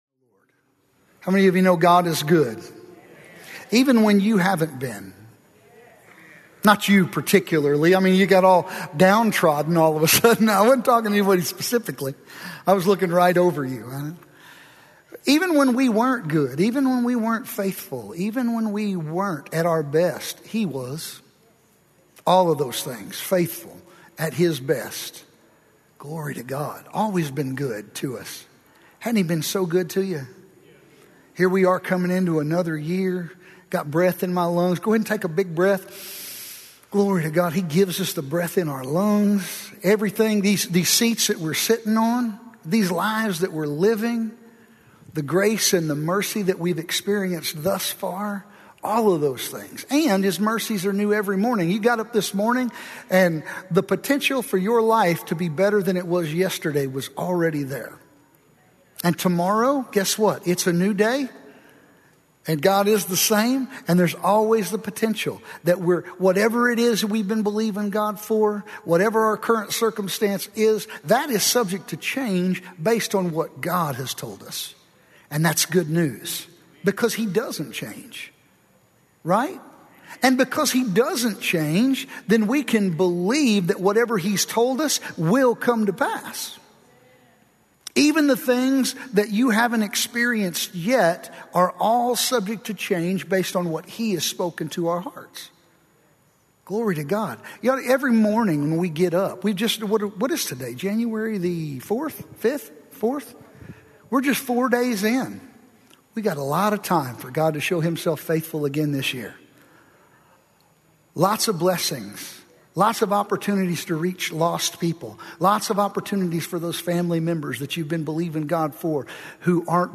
Podcasts for RHEMA Bible Church services held at the Broken Arrow, OK campus.